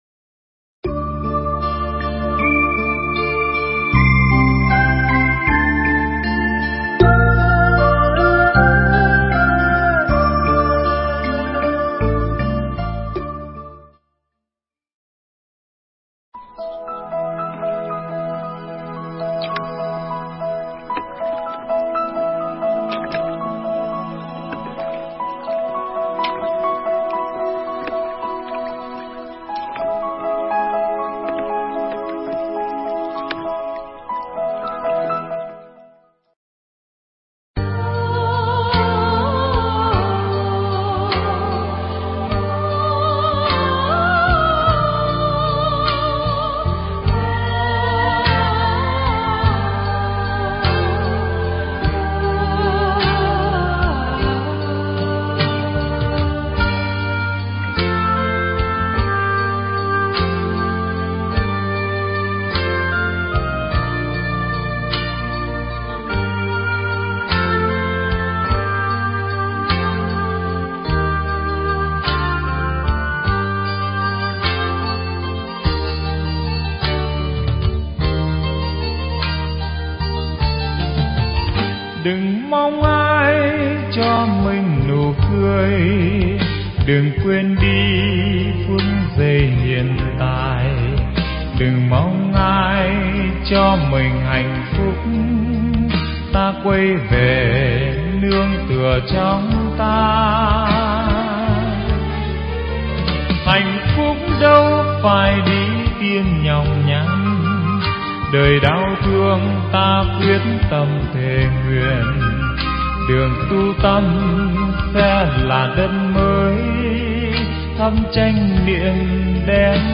Nghe Mp3 thuyết pháp Khởi Phát Chánh Kiến Phần 1
Mp3 pháp thoại Khởi Phát Chánh Kiến Phần 1